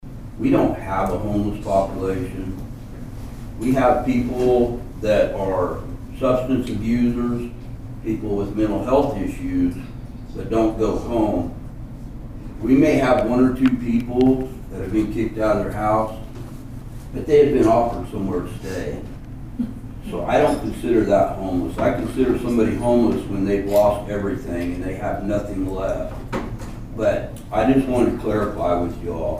The Nowata City Commissioners met for their regularly scheduled meeting in the month of October at the Nowata Fire Department on Monday evening.
Nowata Police Chief Mike McElhaney addressed concerns over a possible homeless population.